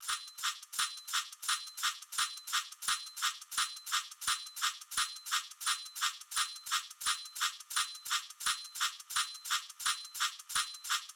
Please try another keyword Drums Drums, Hip Hop, LoFi, Percussion 172 Loop WAV -- Percs 00:00 Leave a Reply Cancel reply Your email address will not be published.
av_mixtape_01_percs_172bpm